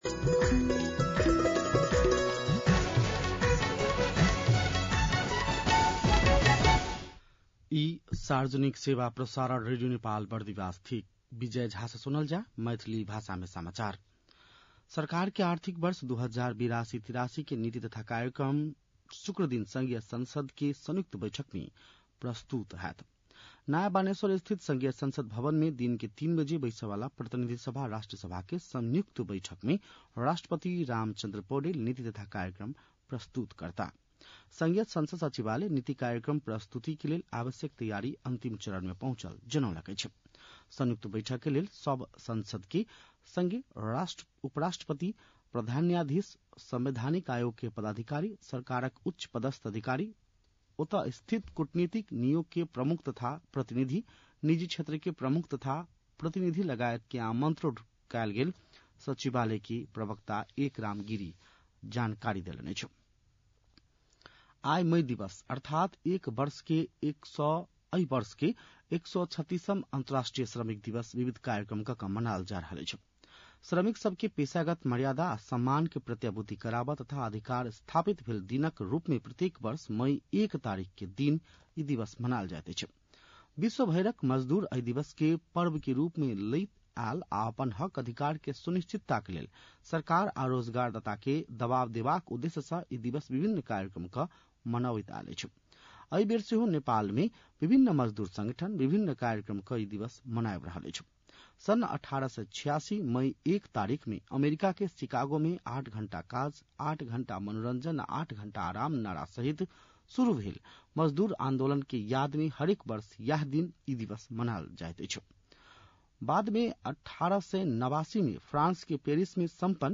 मैथिली भाषामा समाचार : १८ वैशाख , २०८२